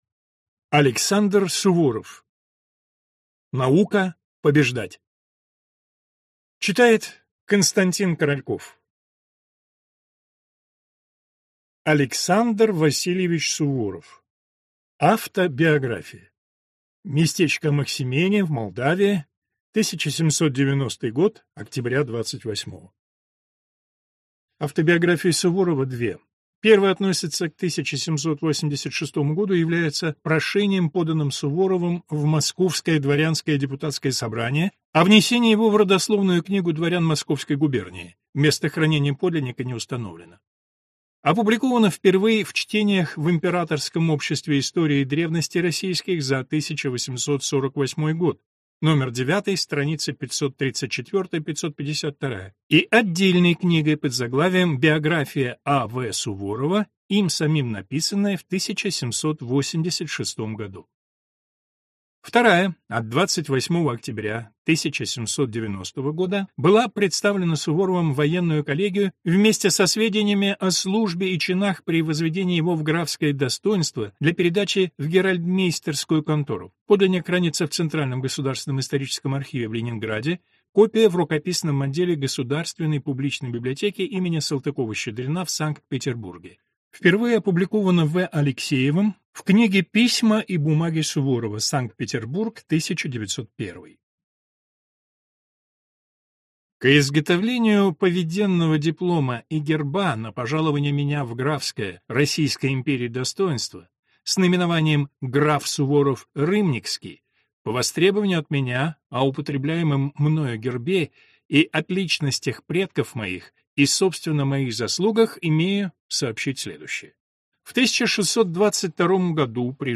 Аудиокнига Наука побеждать (сборник) | Библиотека аудиокниг